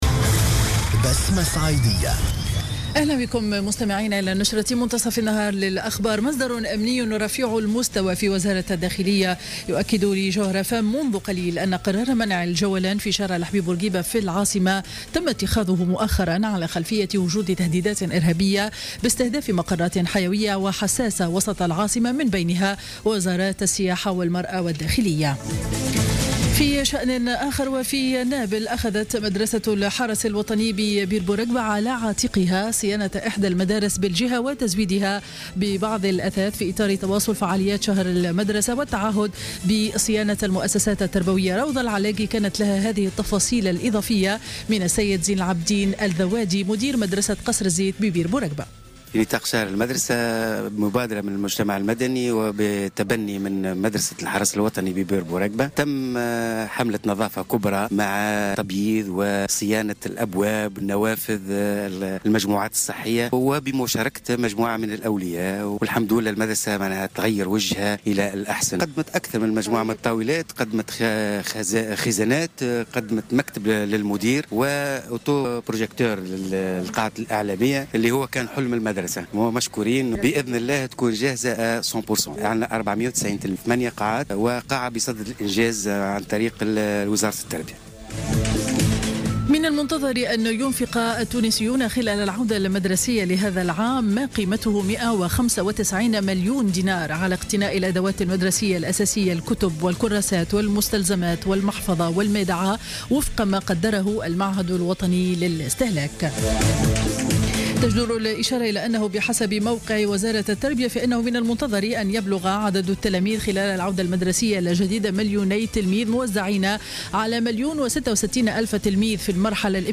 نشرة أخبار منتصف النهار ليوم الأحد 6 سبتمبر 2015